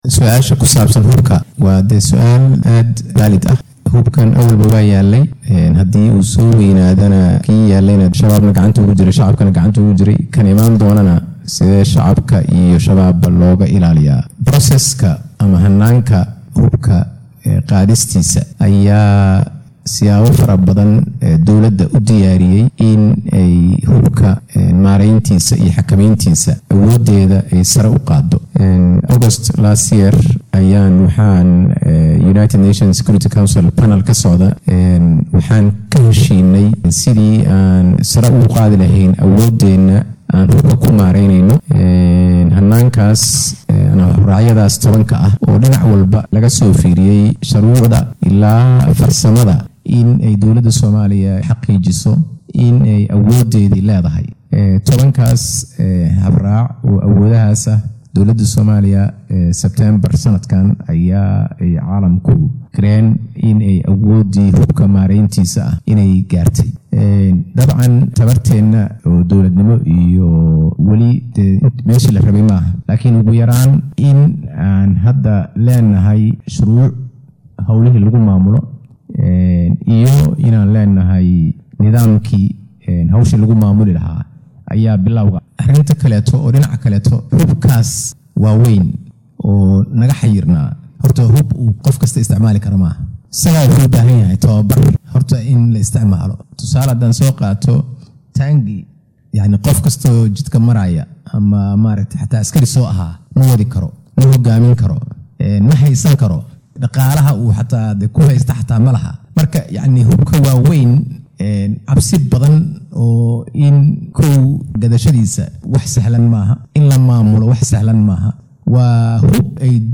La taliyaha Amniga Qaranka ee Madaxweynaha Jamhuuriyadda Federaalka Soomaaliya Xuseen Macalin oo ka jawaabayay su’aallo lagu waydiiyay dood uu xalay ka qayb galay ayaa sheegay inay jiraan tallaabooyin ku aaddan xakameynta hubka .